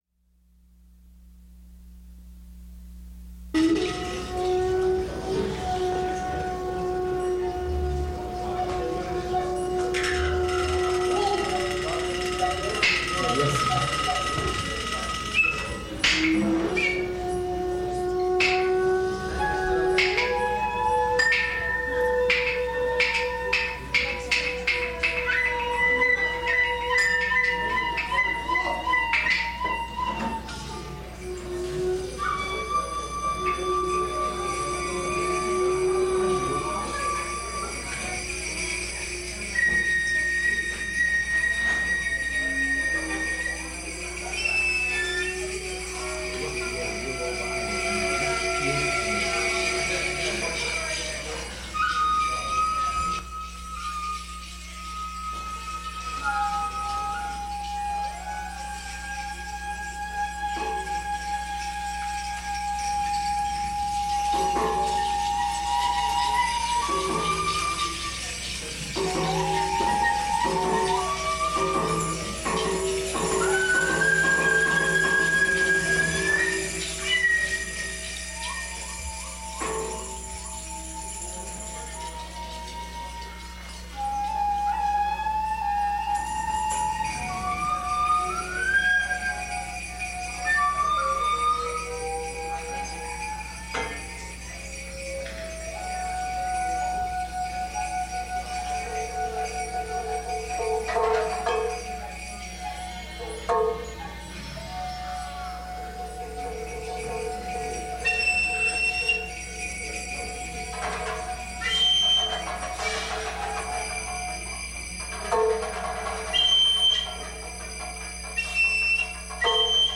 Bass
Drums
Soprano, Alto, Tenor Saxs
Alto Sax, Flute, Fue, Taiko
Guitar
Percussion
On this recording he is playing trap drums.